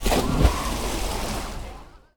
placed.wav